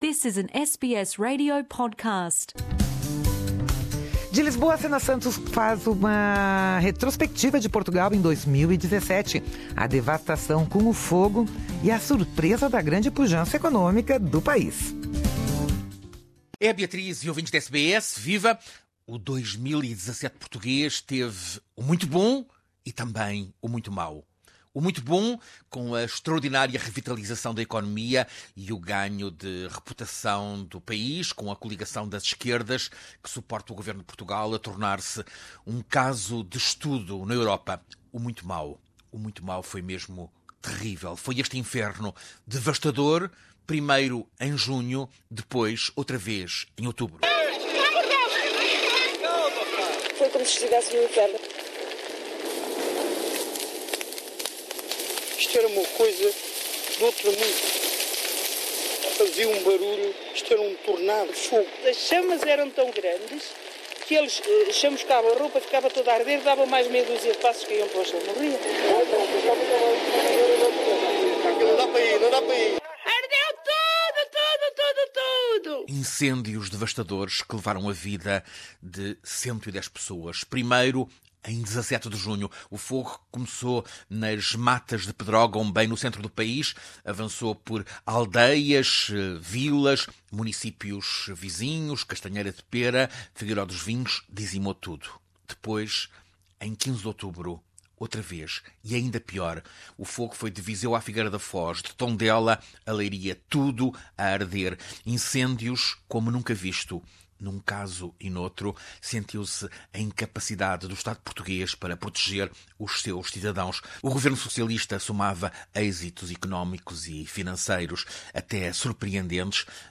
Um balanço deste 2017 em Portugal nesta retrospectiva